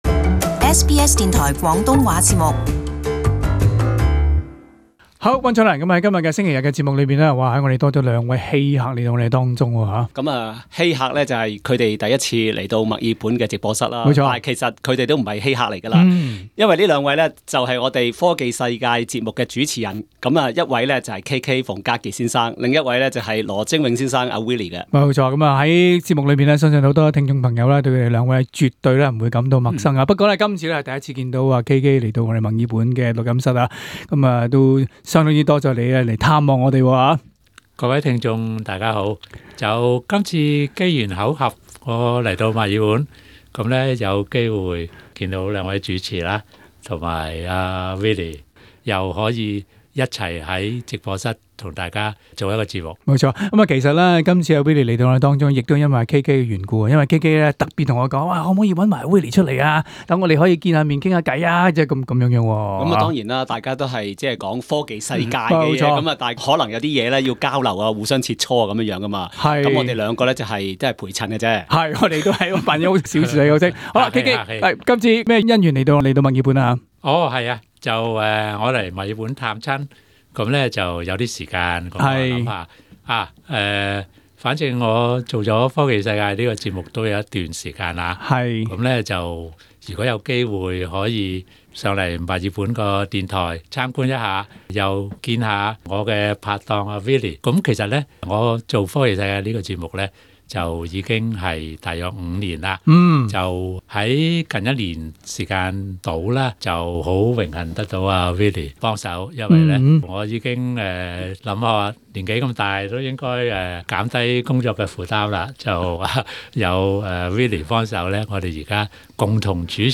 接受訪問，分享他們當年為何選修【科學】學科，之後怎樣開展與科學領域相關的行業經歷。期間兩位嘉賓也分享當今資訊爆炸的年代，大家應當採取什麼態度分析，過濾及吸收網上的資訊。